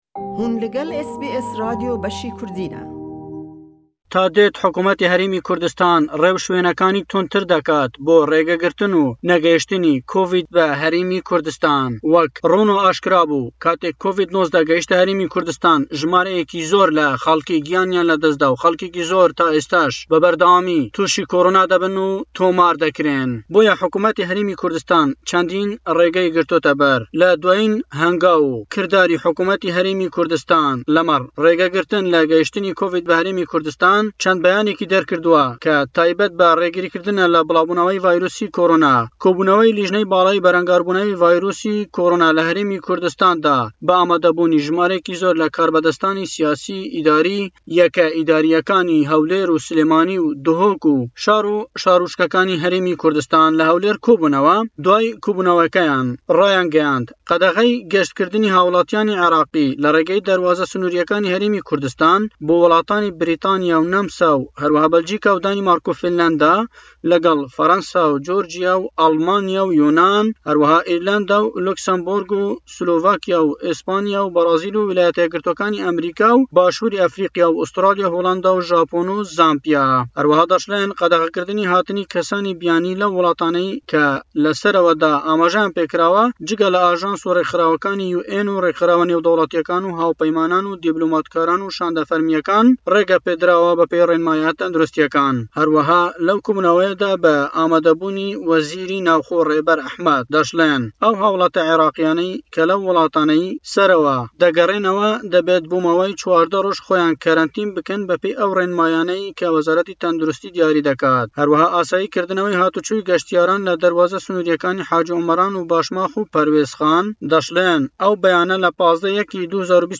Le raportî em hefteye le Hewlêre we Herêmî Kurdistan geştkirdin qedexe dekat bo hawillatîyan Êraqî bo 21 willat ke brîtî ye le Australya, herweha daniştûwanî ew willataneyş boyan nîye geşt biken bo Başûrî Kurdistan. Eme be mebestî rêgirî ye le billawbûnewey corî nwêy COVÎD-19 ke be xêrayî teşene dekat.